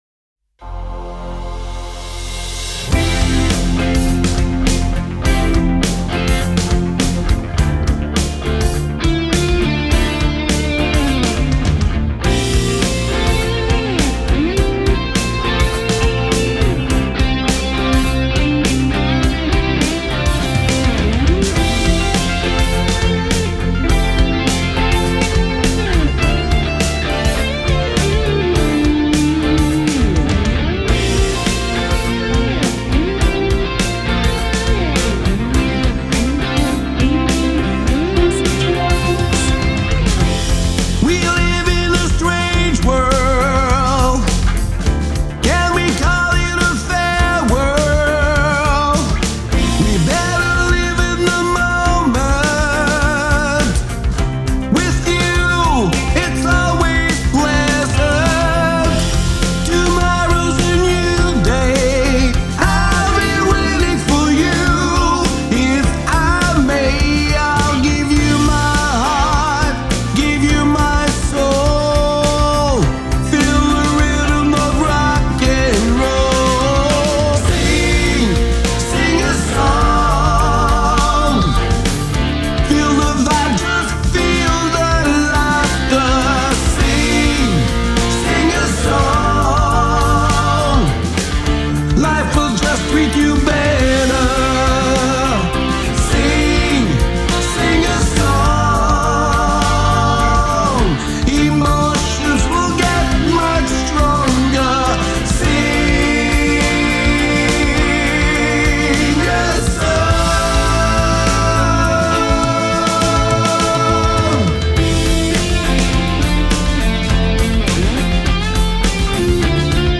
I'm a Logic Pro user and my audio interface is the UAD Apollo Twin x. Because of its virtual console, I have an 1176 compressor and a dynamic EQ on my vocal chain that is printed in my vocal track. My voice is a lot high/mid and harsh which gives me a challenge to mix every time because I'm hard on myself.